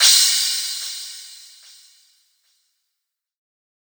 Standard Crash.wav